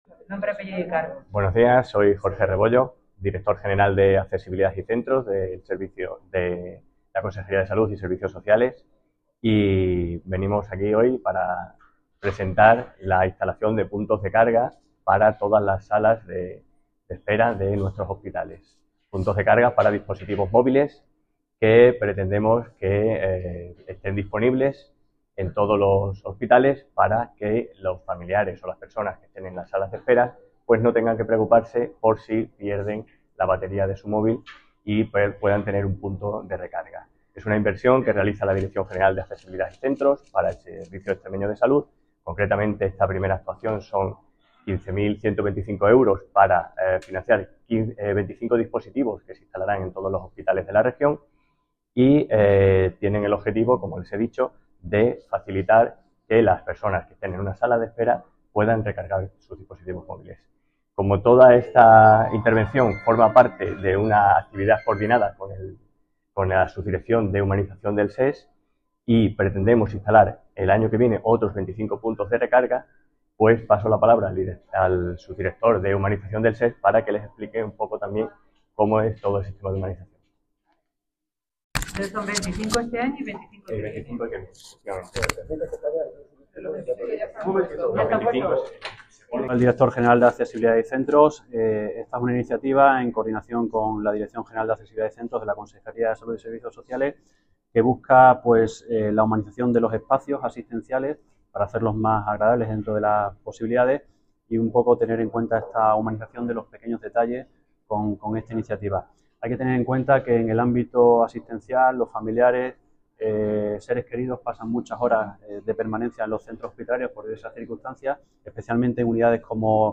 Jorge Rebollo, director general de Accesibilidad y Centros de la Consejer�a de Salud y Servicios Sociales .